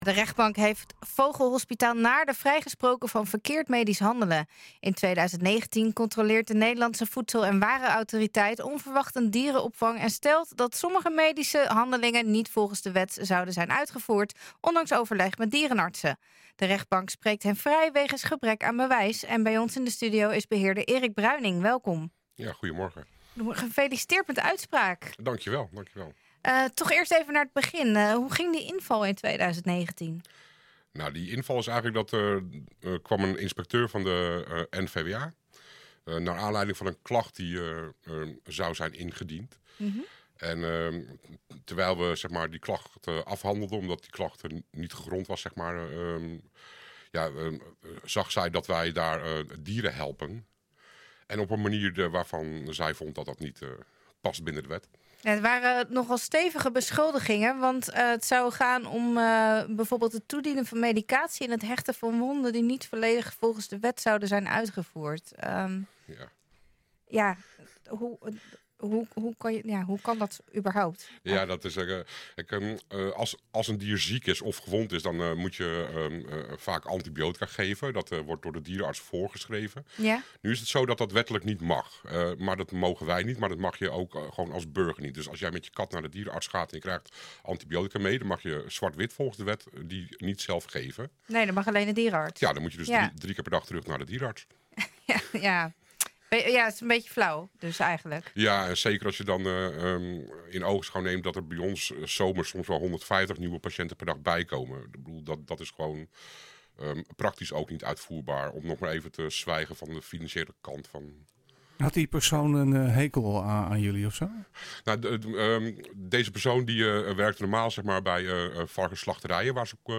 NHGooi is de streekomroep voor Gooi & Vechtstreek.